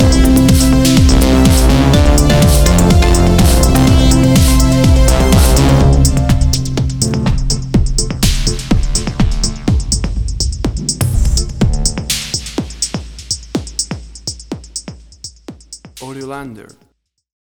WAV Sample Rate: 16-Bit stereo, 44.1 kHz
Tempo (BPM): 125